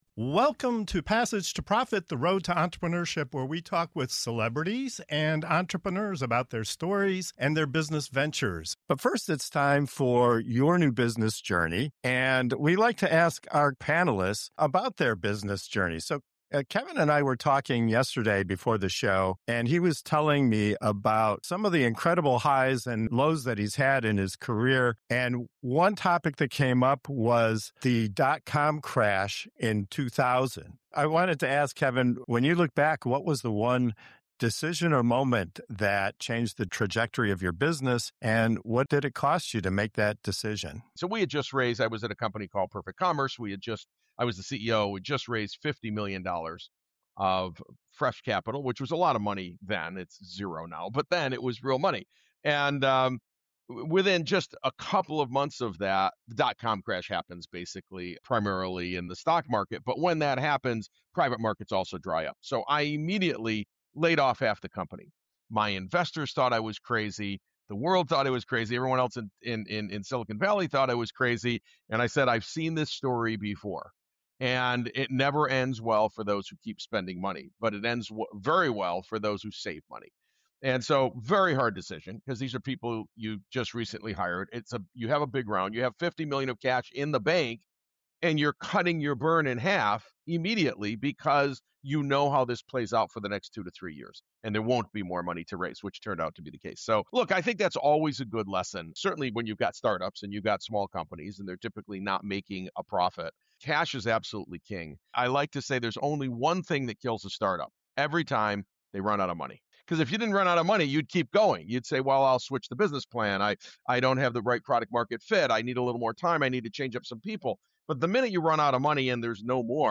In this segment of "Your New Business Journey" on Passage to Profit Show, seasoned entrepreneurs share the pivotal decisions that changed the trajectory of their businesses—from surviving the dot-com crash and safeguarding cash flow to taking bold risks that unlocked growth.